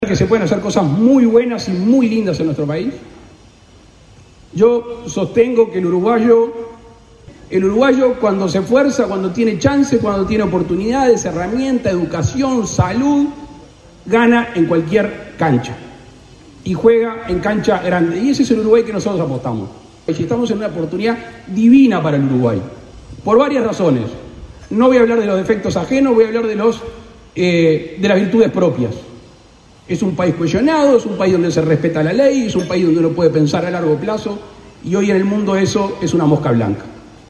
El presidente de la República participó de una inauguración en Punta del Este, Maldonado.